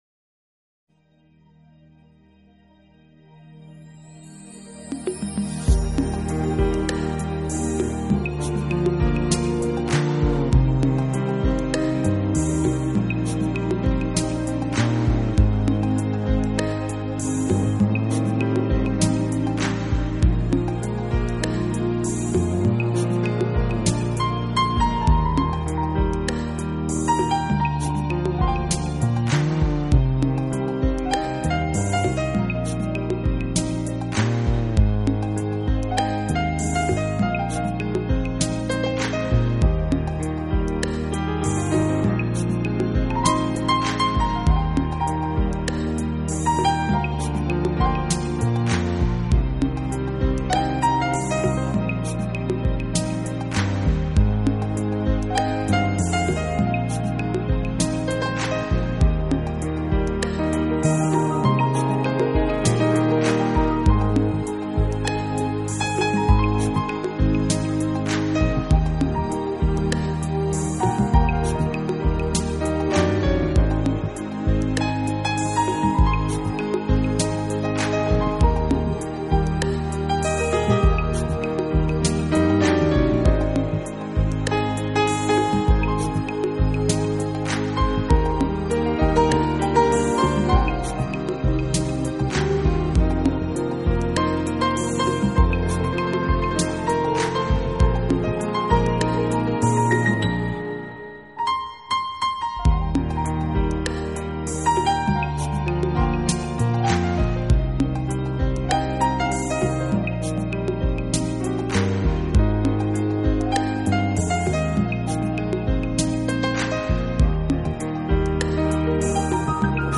名品钢琴